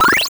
charge.wav